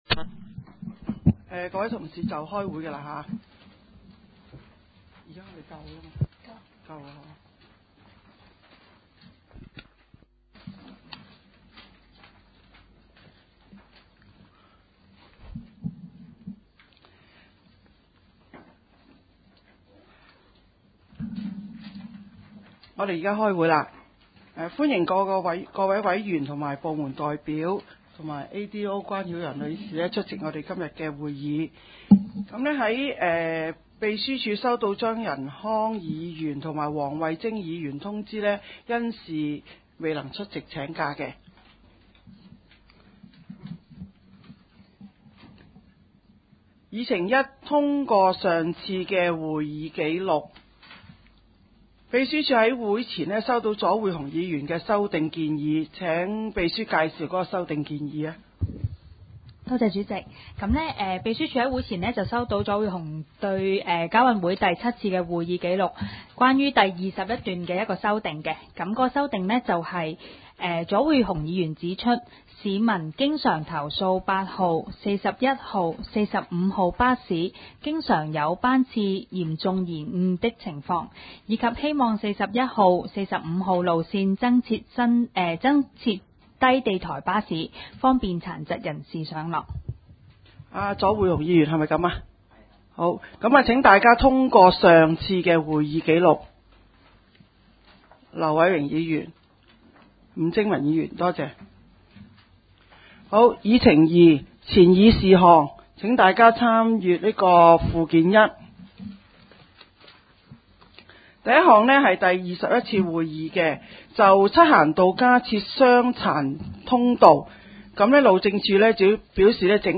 九龍城區議會轄下 交通及運輸事務委員會 第八次會議 日期： 2009 年 4 月 9 日 ( 星期四 ) 時間： 下午 2 時 30 分 地點： 九龍紅磡德豐街 18-22 號 海濱廣場一座 17 樓 九龍城民政事務處會議室 議 程 （會議錄音） 文件編號 1.